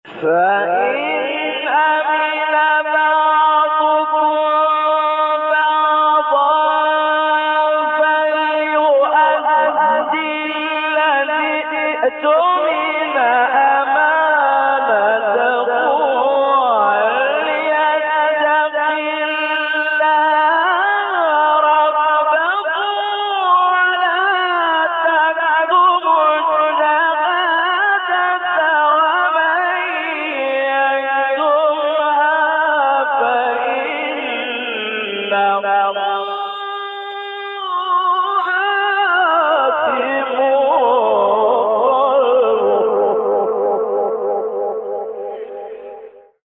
گروه فعالیت‌های قرآنی: مقاطع صوتی با صدای قاریان ممتاز کشور مصر را می‌شنوید.
قطعه‌ای از محمد اللیثی در مقام بیات